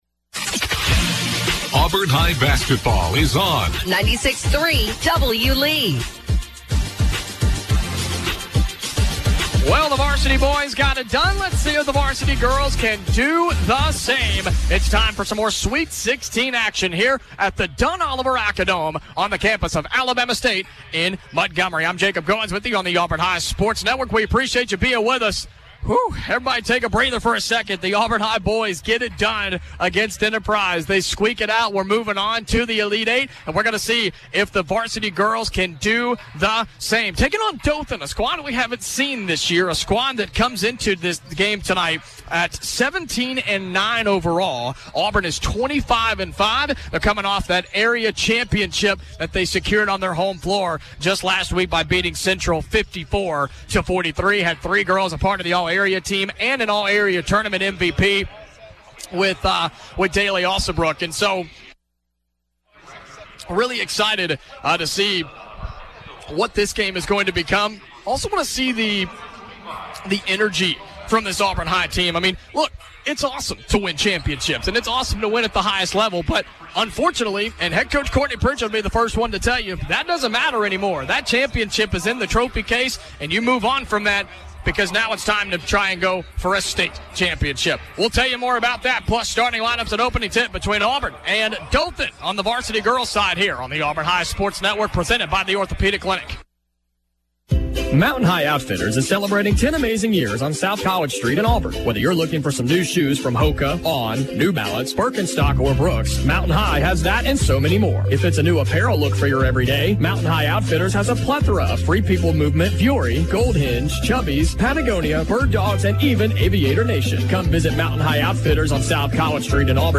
as he calls Auburn High's game versus Dothan in the State Tournament Sweet Sixteen. The Tigers won 72-31.